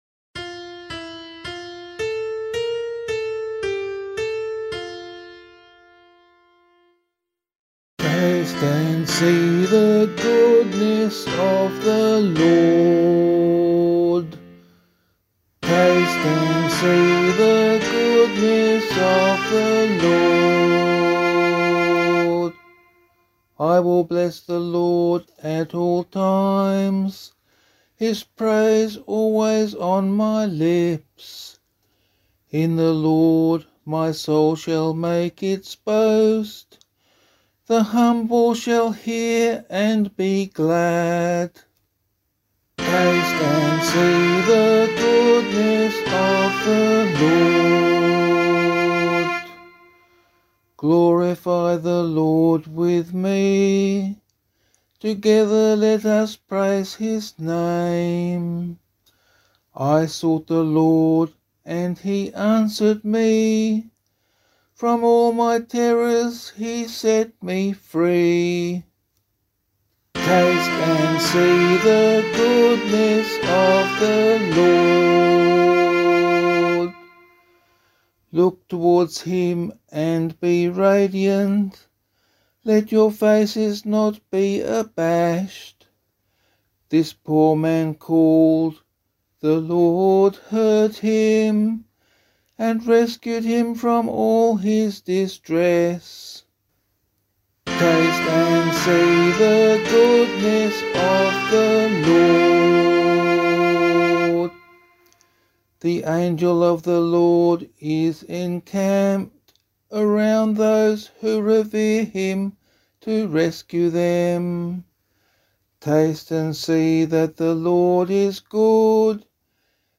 Meinrad Psalm Tone